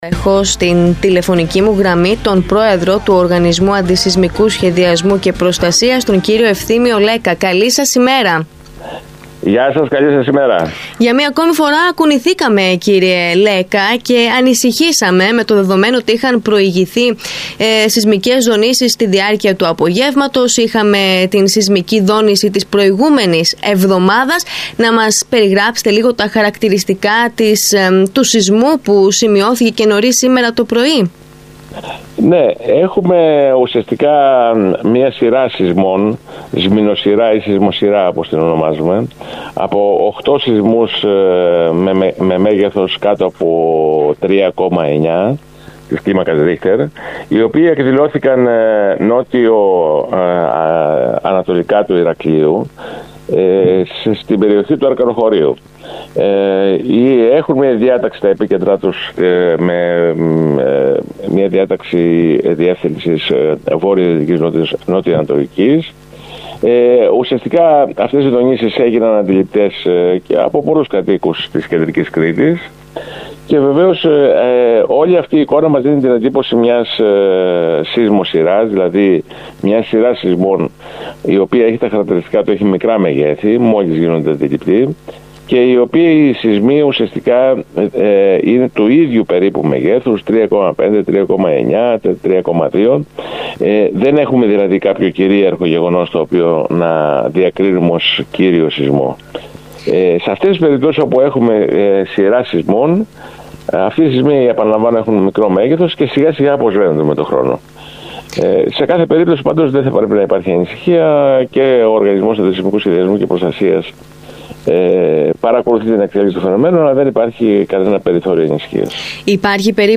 Λέκκας στον ΣΚΑΪ Κρήτης: Τι είναι η σεισμοσειρά που αναστατώνει το Ηράκλειο – Δεν απέκλεισε νέο μεγαλύτερο σεισμό